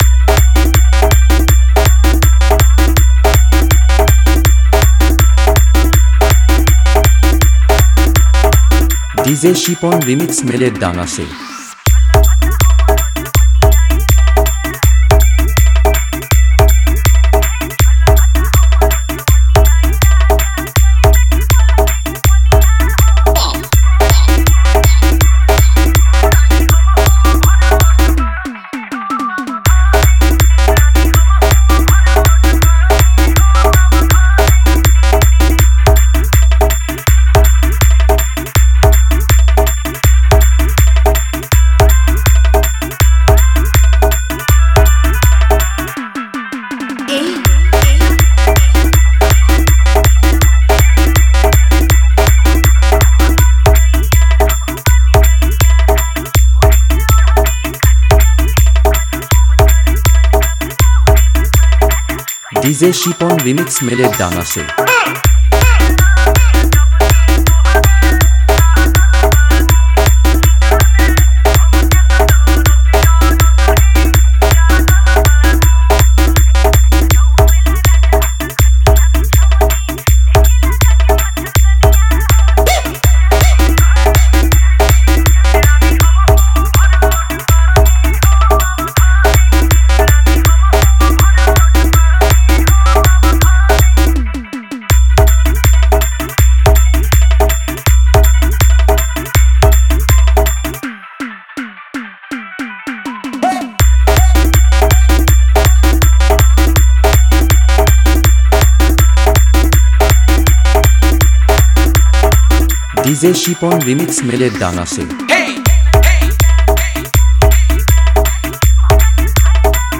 Bhojpuri Dance Humming Bass Mix song new 2025